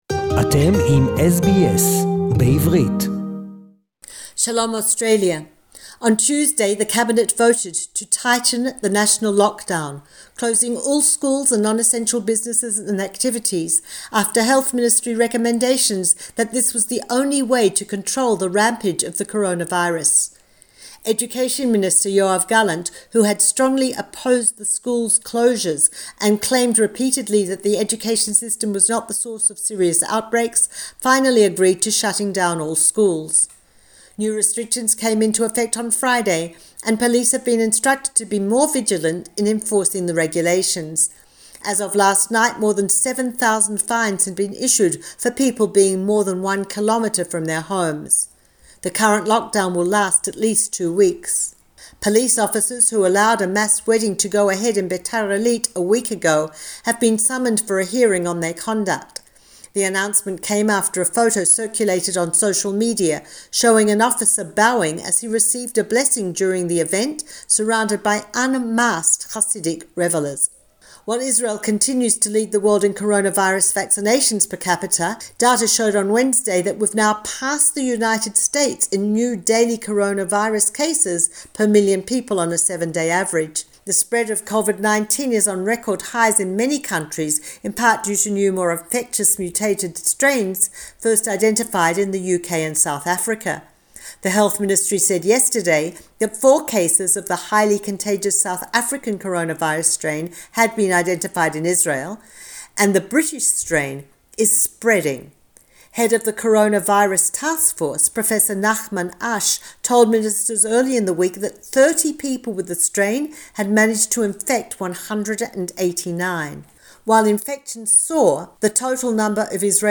SBS Jerusalem report in English